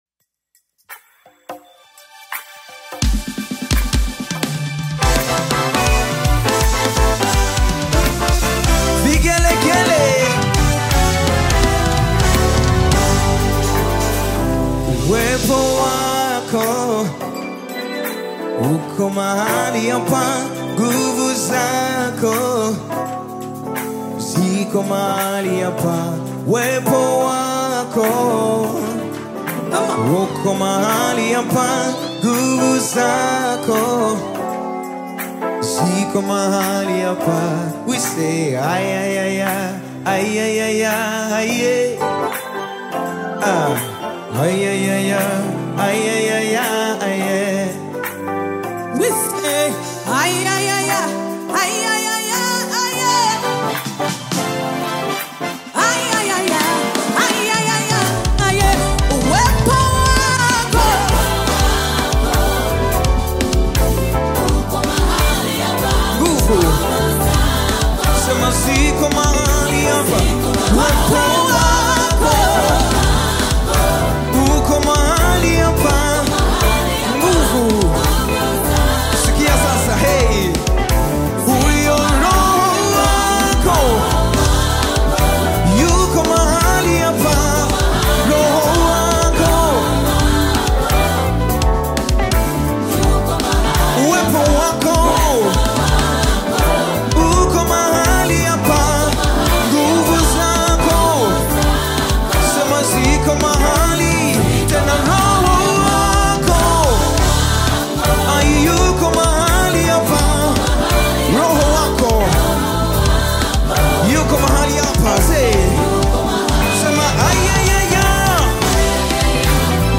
Nyimbo za Dini music
Gospel music track
Tanzanian gospel artists